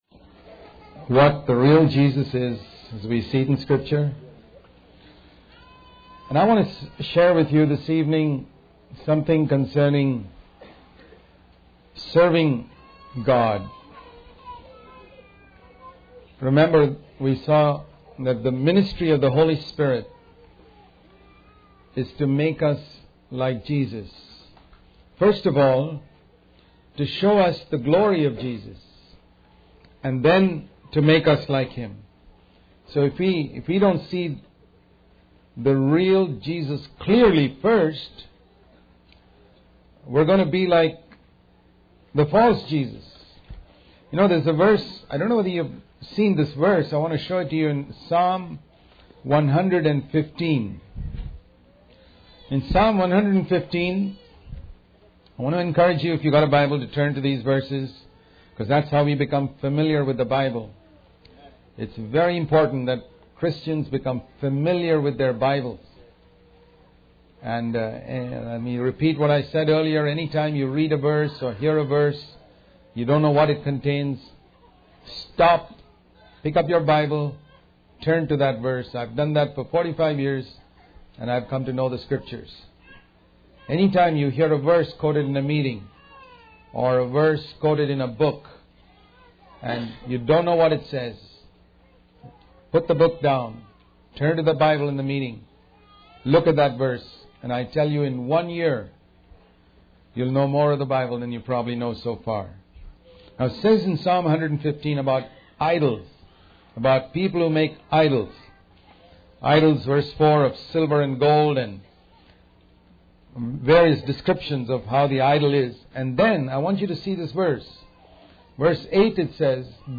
In this sermon, the speaker emphasizes the importance of finishing the work that God has given us to do. He references John 17:4, where Jesus declares that he has glorified God on earth by completing the work assigned to him.